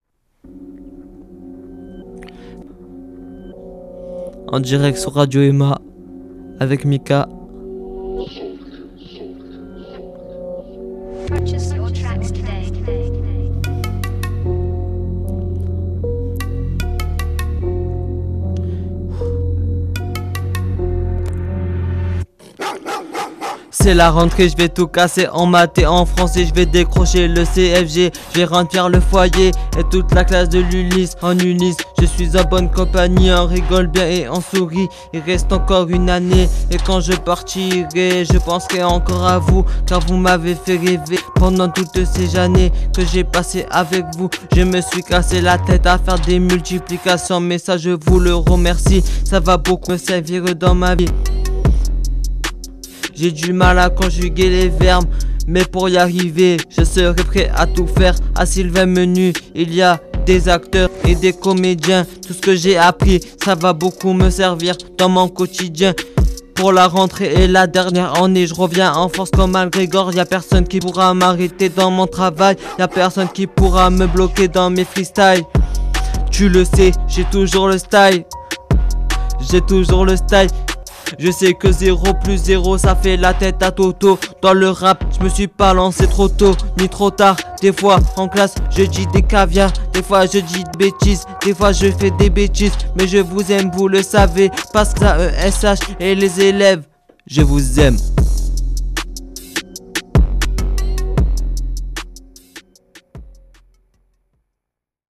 Freestyle de l'espace!! Le texte, le flow, tout y est!!
Bonne écoute et bravo au rappeur pour ce bonheur!